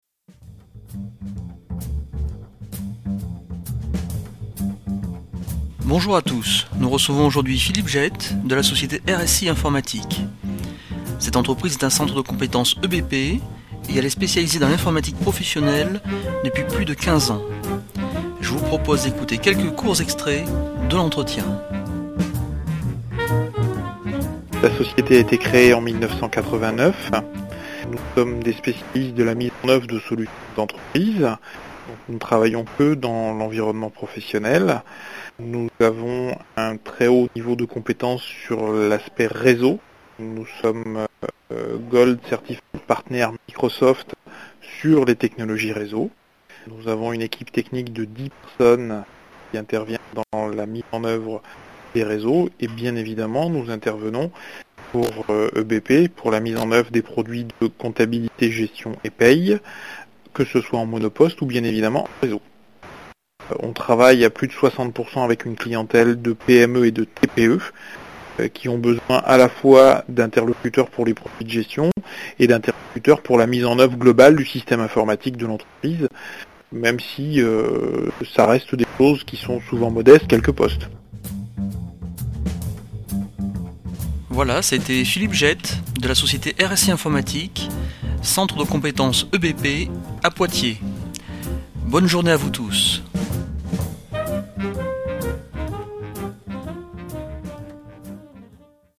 interview-rsi.mp3